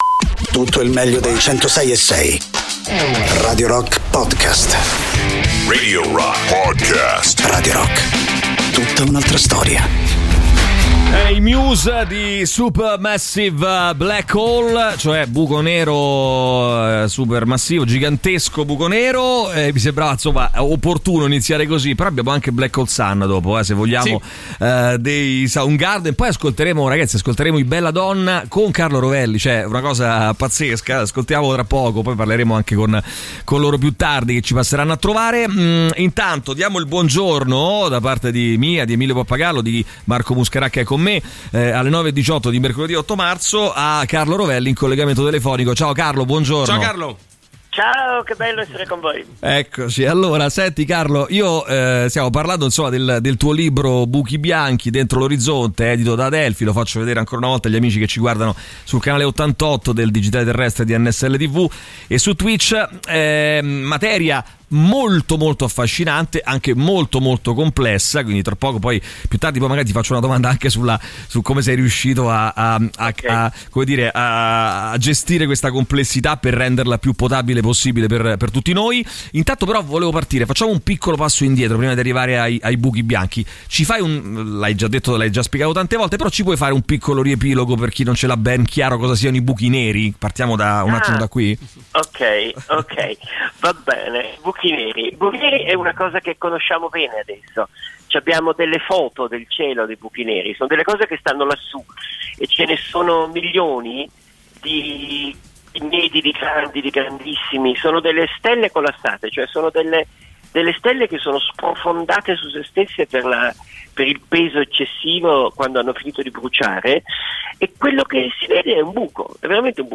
Carlo Rovelli , scrittore, ospite telefonico